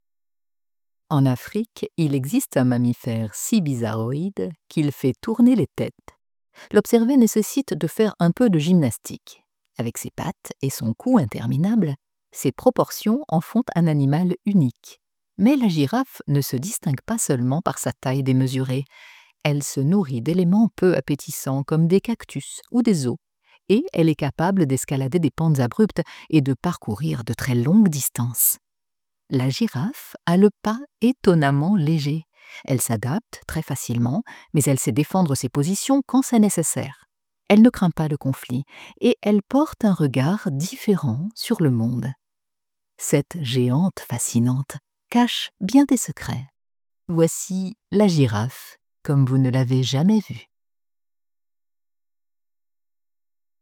Narration - Voix douce
- Mezzo-soprano